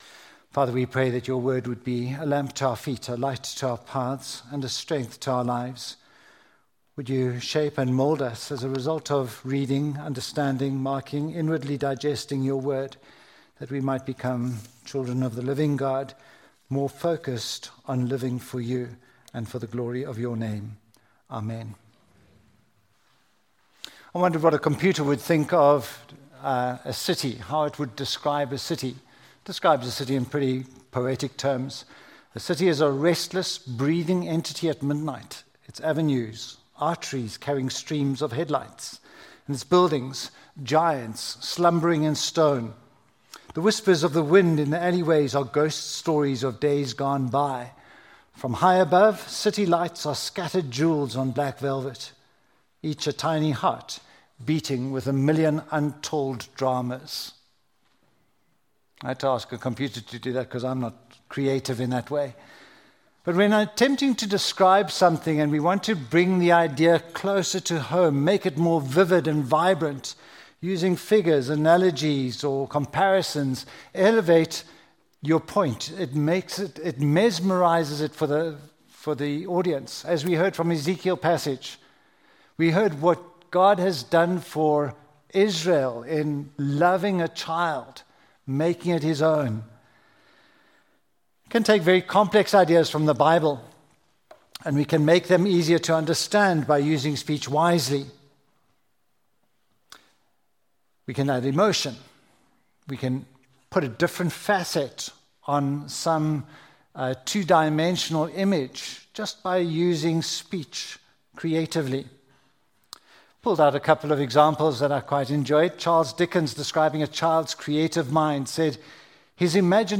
1Peter 2:1-8 Service Type: Sunday Morning Baby Living stones in a house Chosen people Holy/Royal Priesthood Holy Nation A people belonging to God « Greetings Thankful!